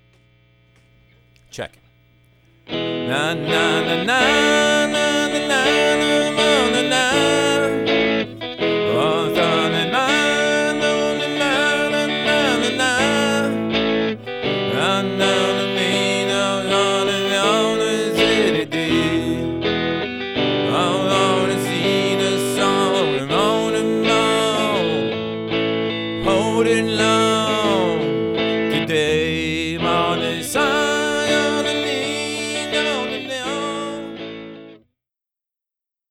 :eek: Excuse the out of tune guitar and jibberish lyrics. I just wanted to put it down quick.